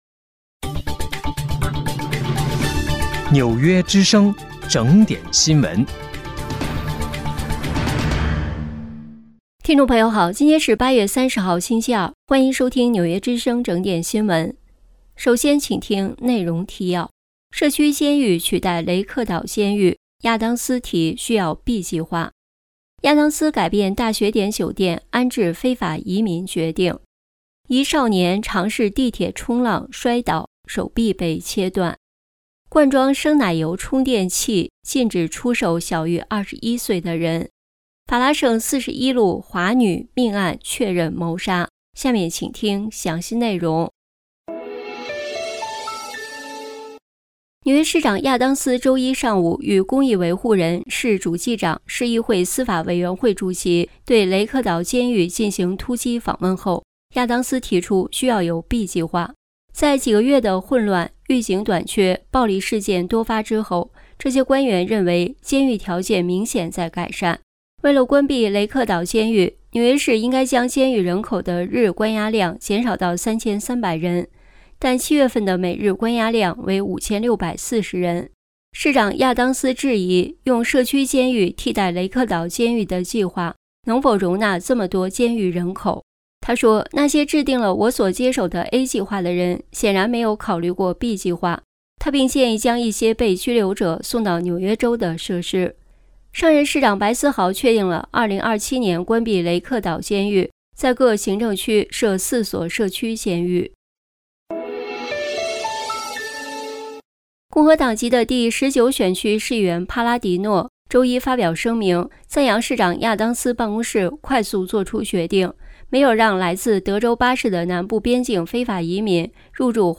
8月30日（星期二）纽约整点新闻